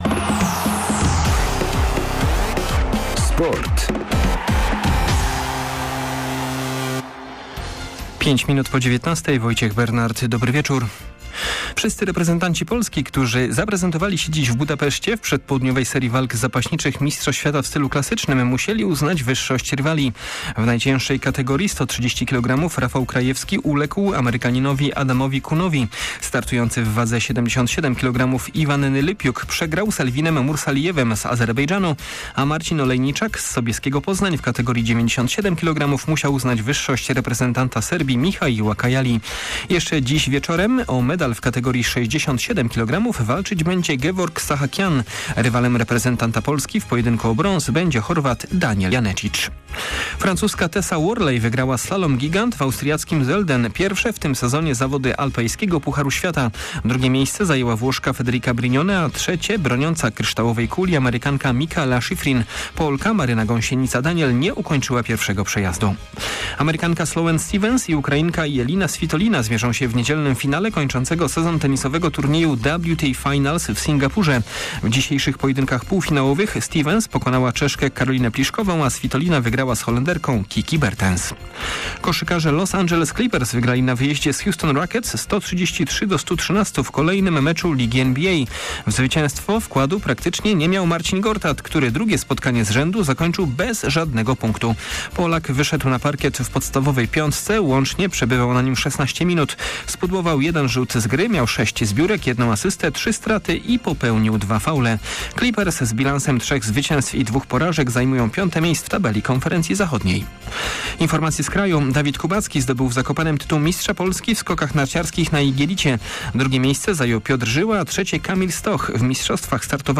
27.10. SERWIS SPORTOWY GODZ. 19:05